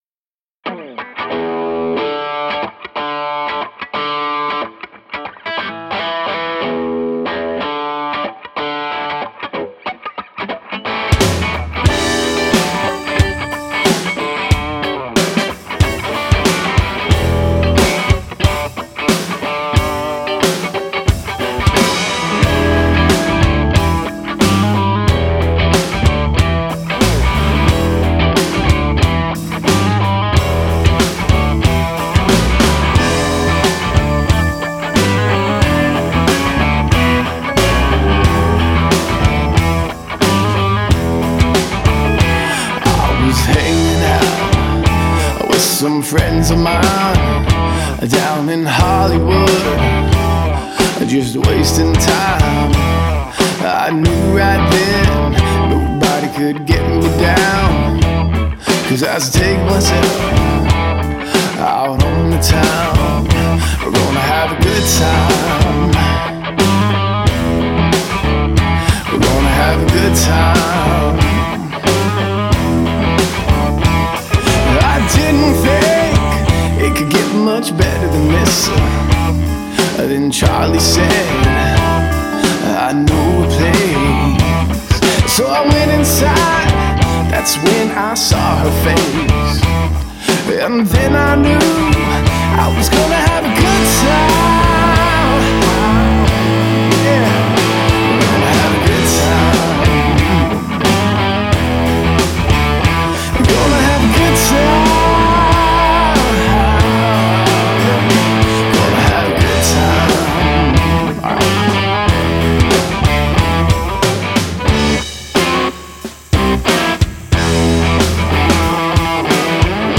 Оцените моё сведение Blues Rock